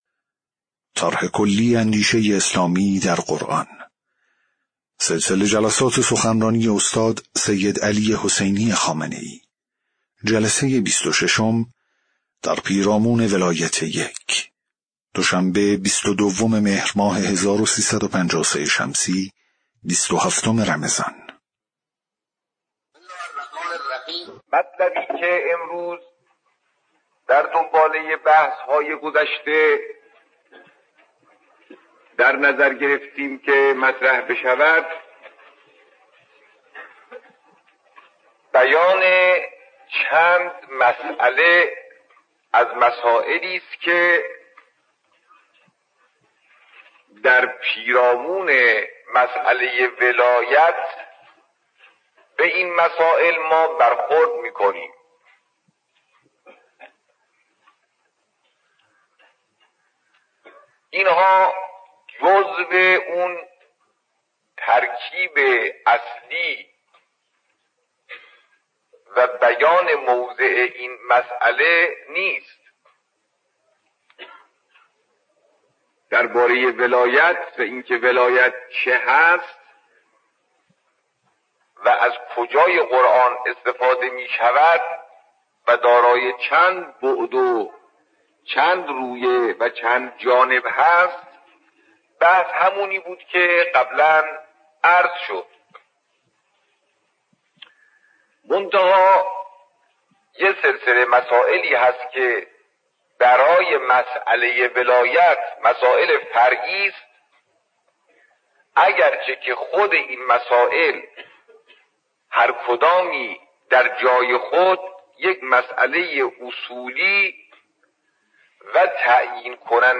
سخنرانی قدیمی